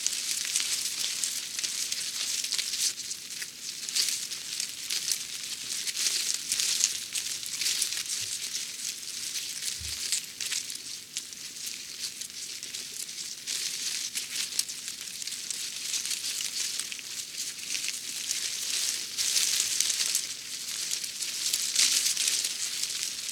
windbush_3.ogg